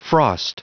Prononciation du mot frost en anglais (fichier audio)
Prononciation du mot : frost